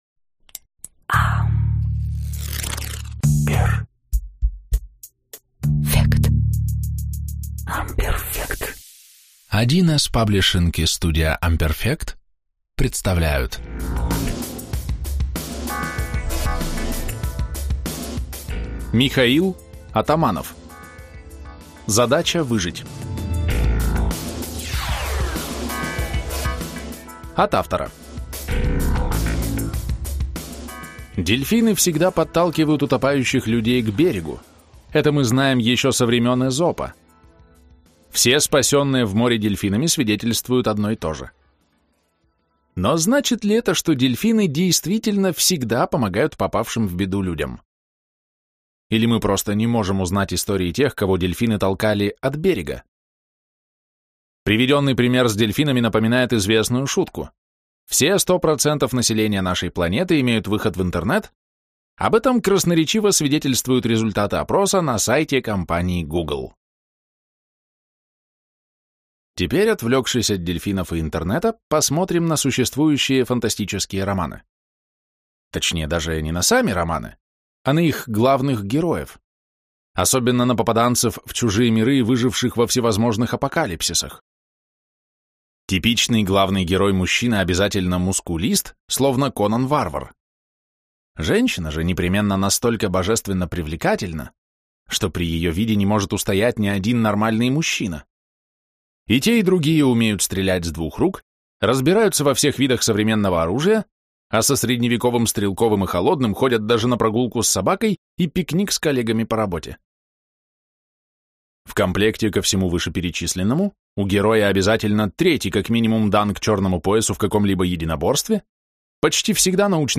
Аудиокнига Задача выжить | Библиотека аудиокниг